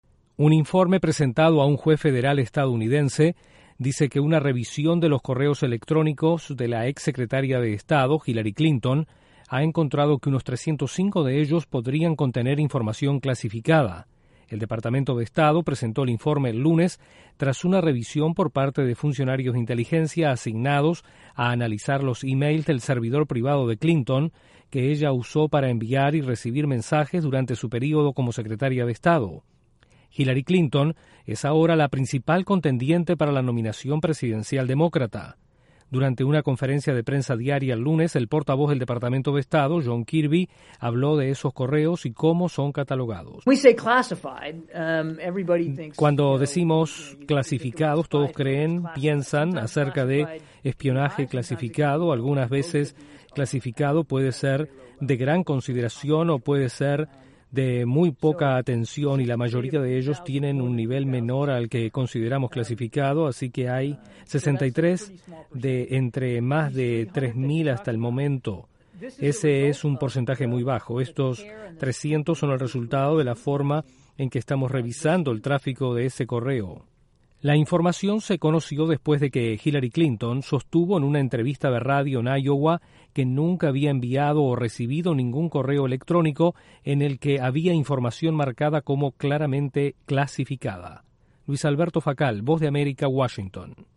Más de 300 emails que envió o reicibió Hillary Clinton desde su servidor privado durante su periodo en el Departamento de Estado serían clasificados. Desde la Voz de América en Washington informa